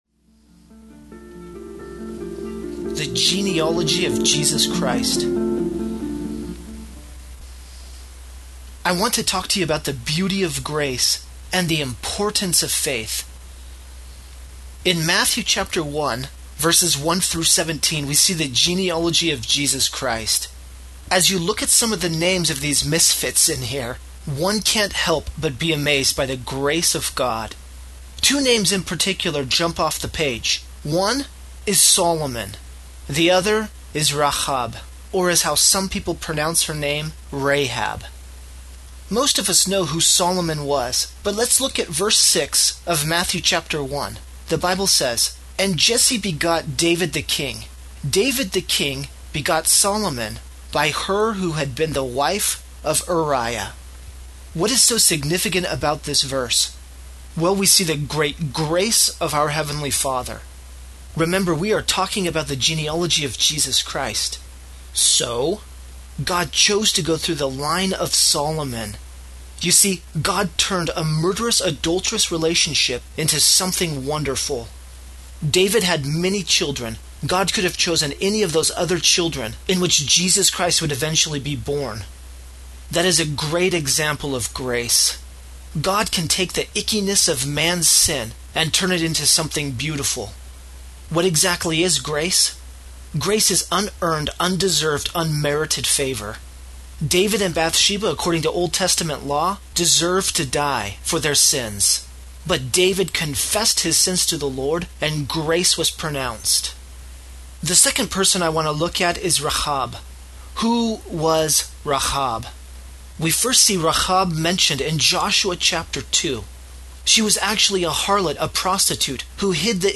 matthew_1_1_the_genealogy_of_jesus_christ.mp3